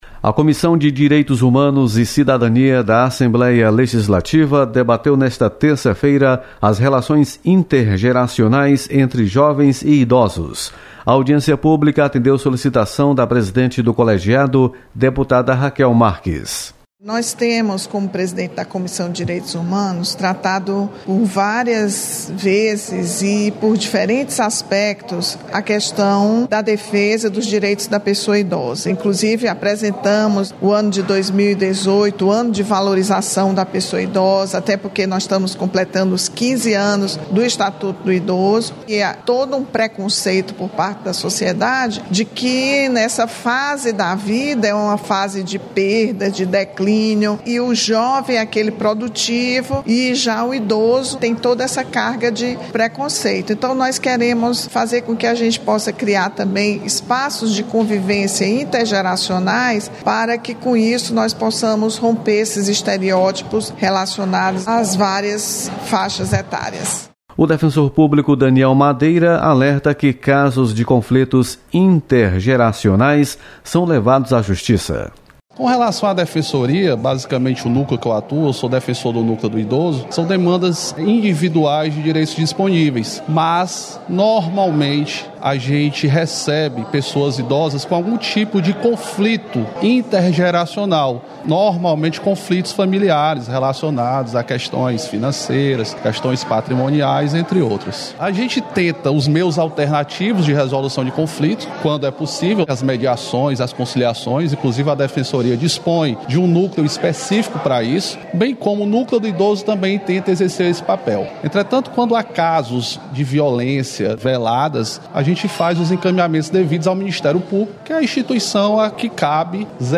Comissão de Direitos Humanos e Cidadania debate relações intergeracionais. Repórter